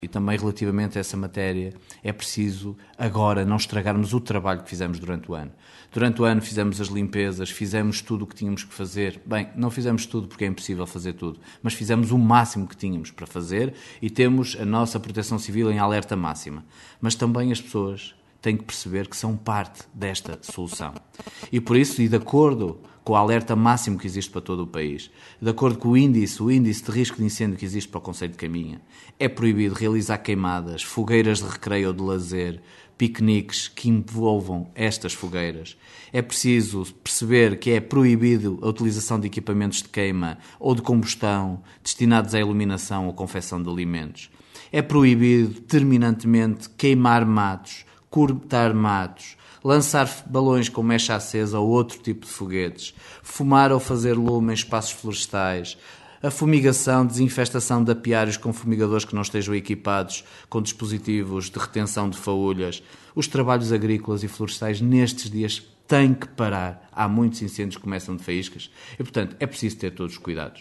Em declarações ao Jornal C o presidente da Comissão Distrital de Proteção Civil, diz que a situação de calor se vai manter durante o fim de semana.